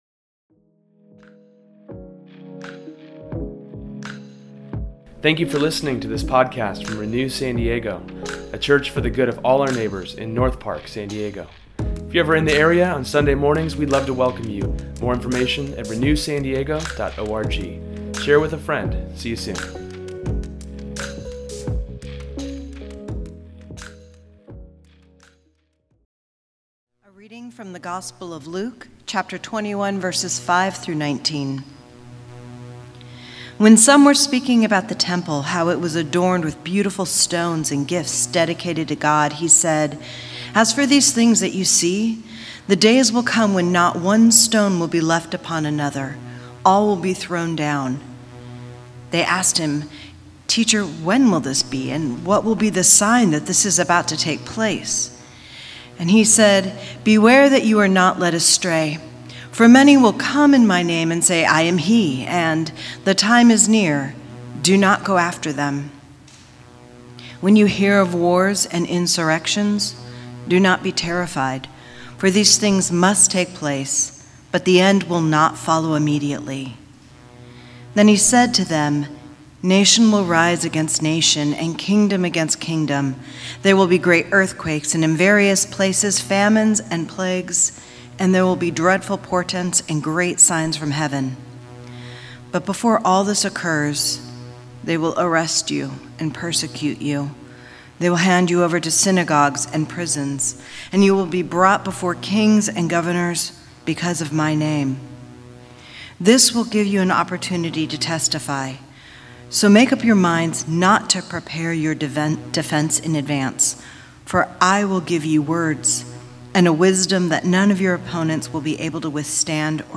Today’s sermon deals with being a disciple for Jesus, and how being baptized and serving him will lead to new things in our lives.